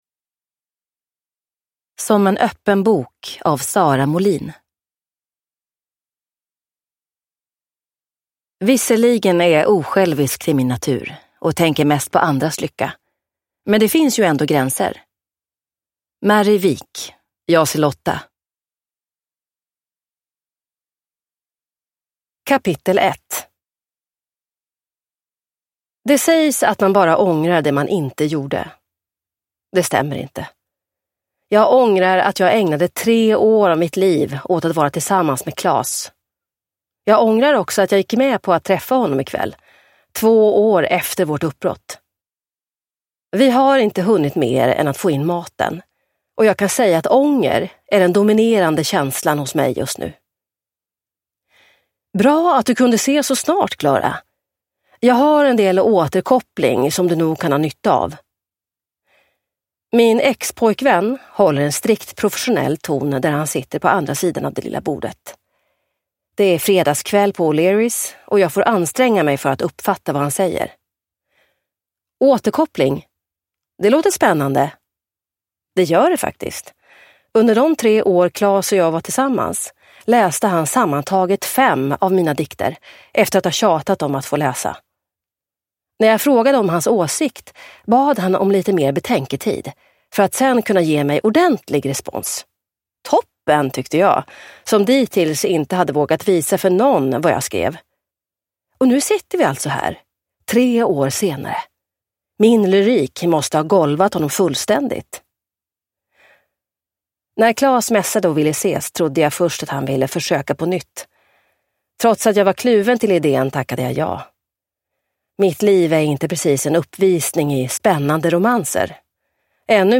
Som en öppen bok – Ljudbok – Laddas ner